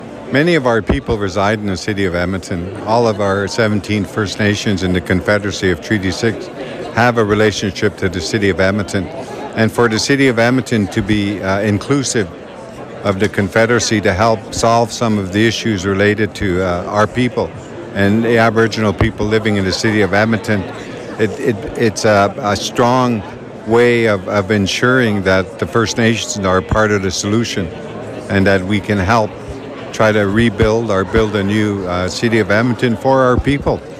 Chief Arcand adds that many citizens from Treaty Six reside within the City of Edmonton, with the relationships that are continuing to be built with the 17 Nations, is a step towards a strong way that First Nations are part of the solution of building a City of Edmonton for Treaty Six people.